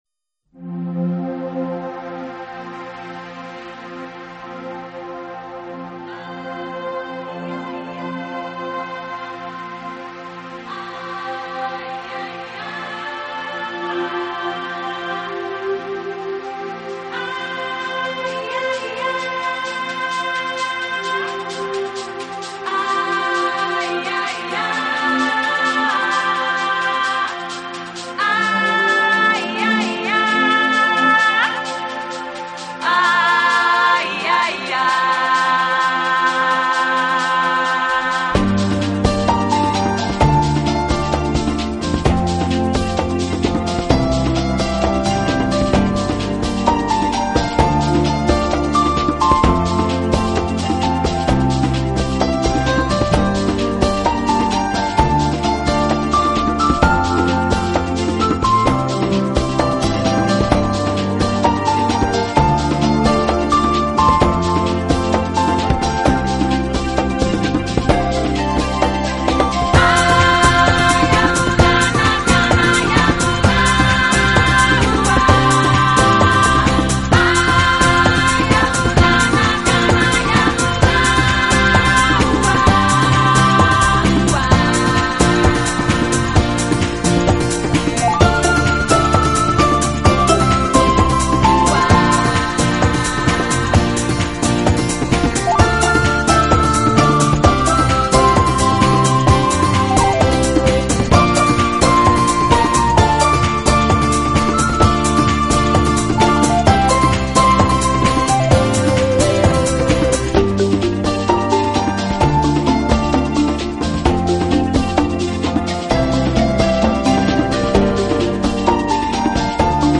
纯音乐
笛子的高亮和女声的欢唱都给音乐带来丰富的层次感和更深的品味感。